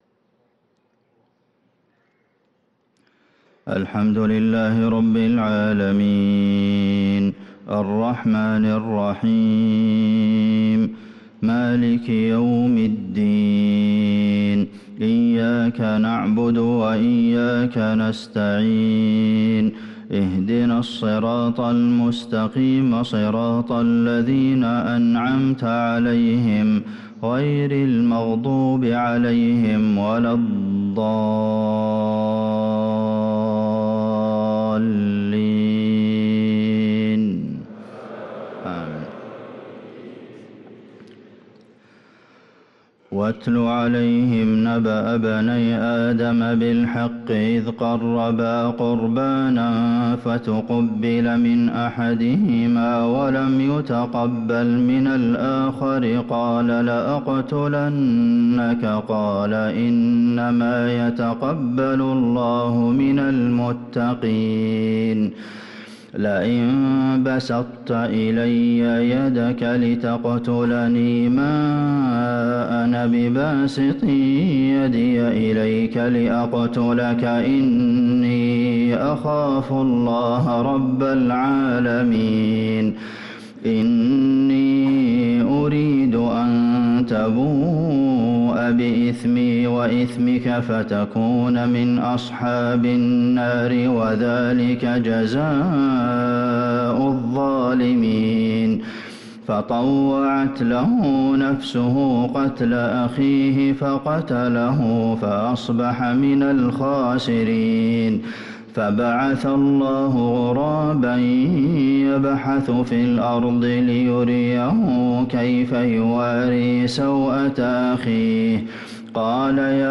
صلاة الفجر للقارئ عبدالمحسن القاسم 5 ربيع الأول 1445 هـ
تِلَاوَات الْحَرَمَيْن .